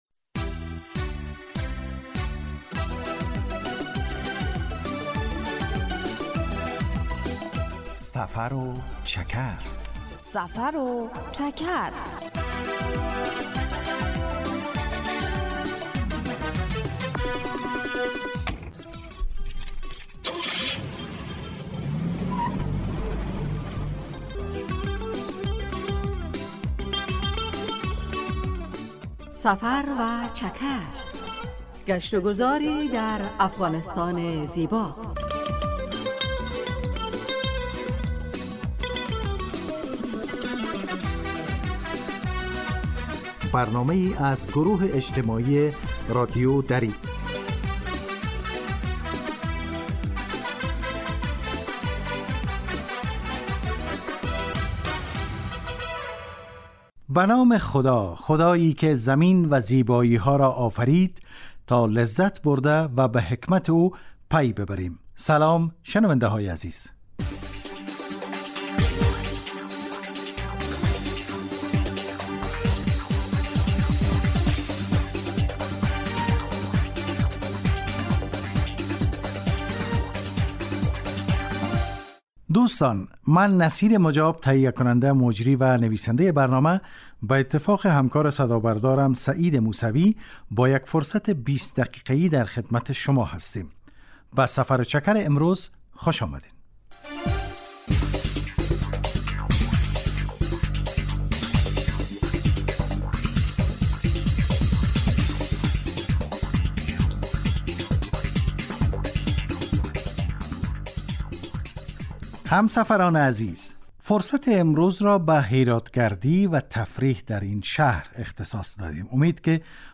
گزارش در باره ورزشکاران رشته سایکل سواری و علاقه جوانان.